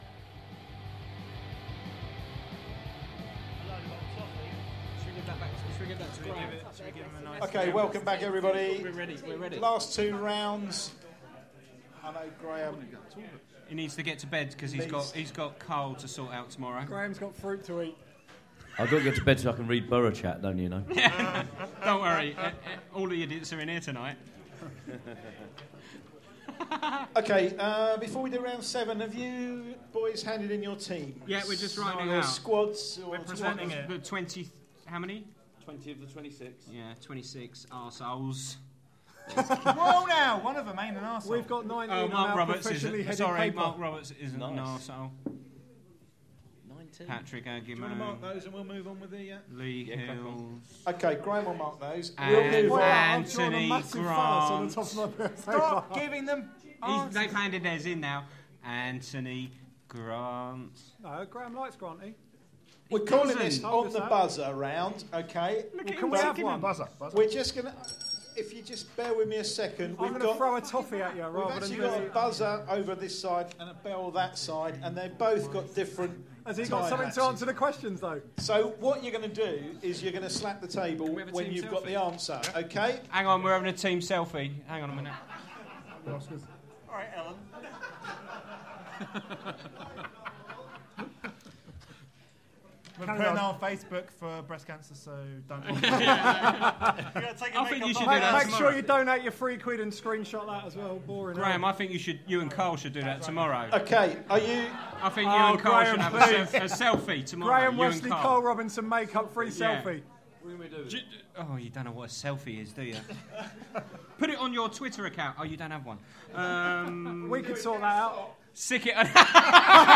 The third and final part of the ALOOT live special recorded in spring 2014
WARNING: Contains strong language